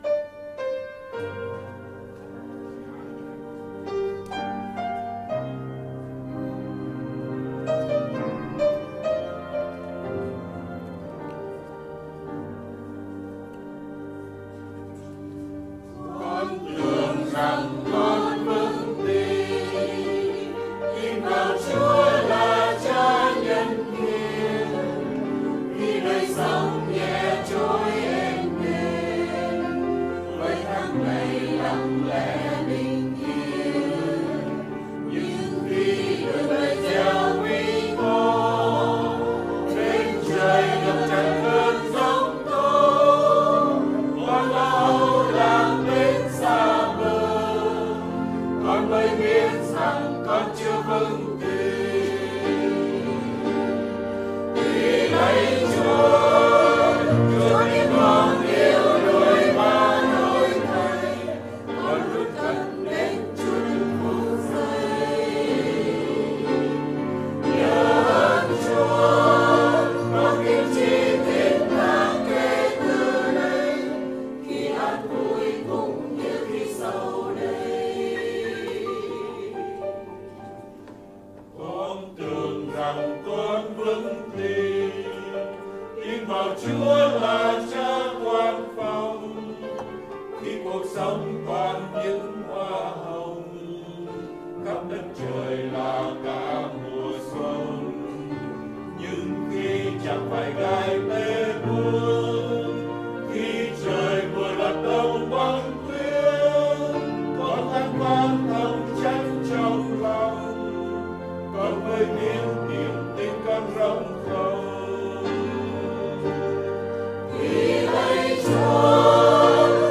Thánh Ca